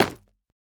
Minecraft Version Minecraft Version latest Latest Release | Latest Snapshot latest / assets / minecraft / sounds / block / nether_bricks / break4.ogg Compare With Compare With Latest Release | Latest Snapshot
break4.ogg